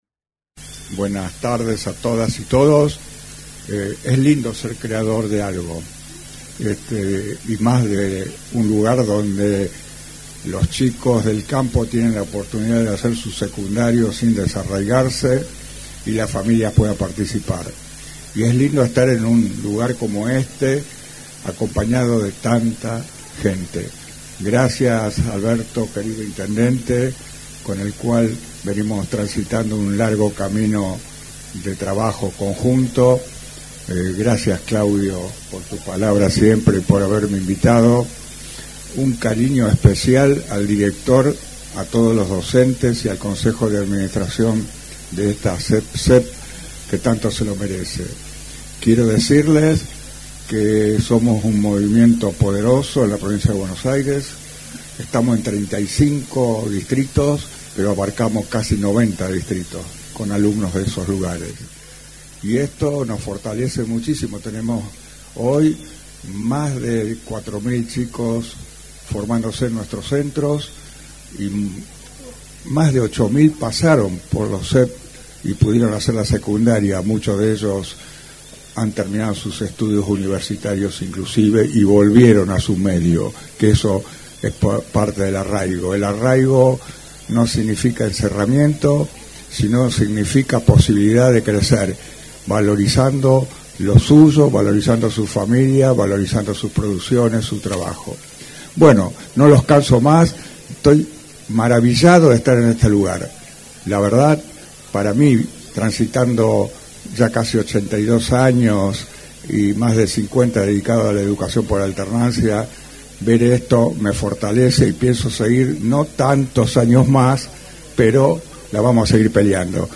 En lo protocolar, se escuchó la reflexión de: